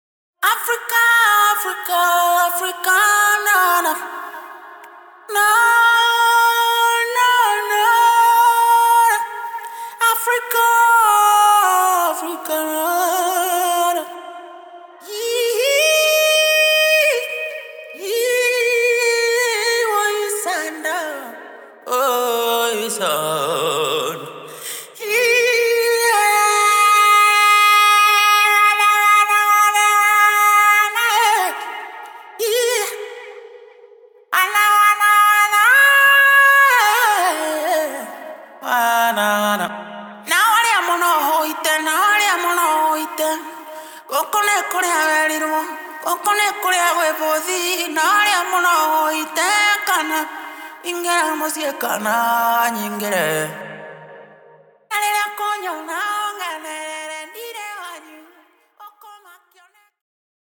Coming from Kenya, sung in Kikuyu language.